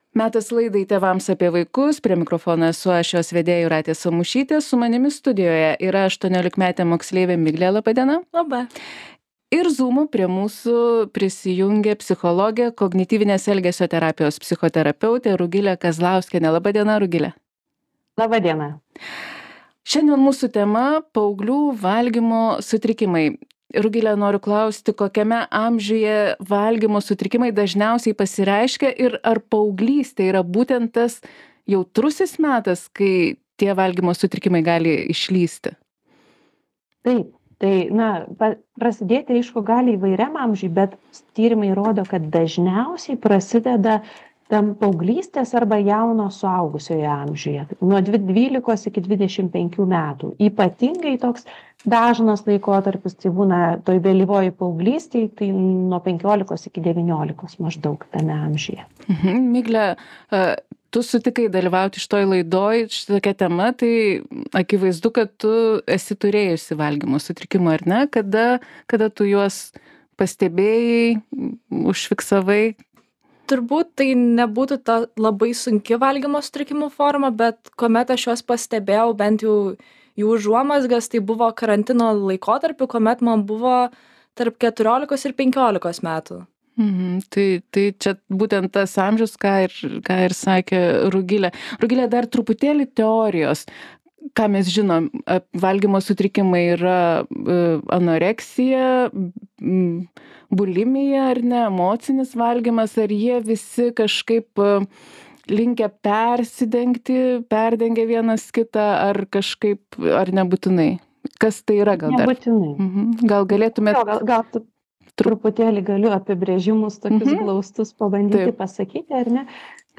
apie priežastis, pasekmes ir pagalbos būdus pasakoja psichologė, kognityvinės elgesio terapijos psichoterapeutė